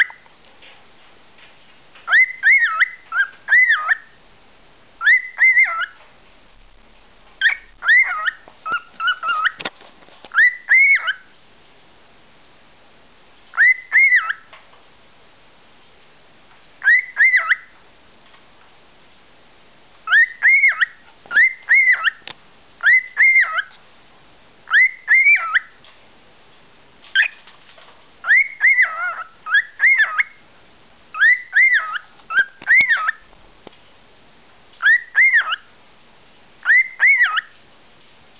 Balzende Hähne
Dieser Hahn baut lautstarke Kontaktrufe in seinen Gesang ein.
singenderhahn6.wav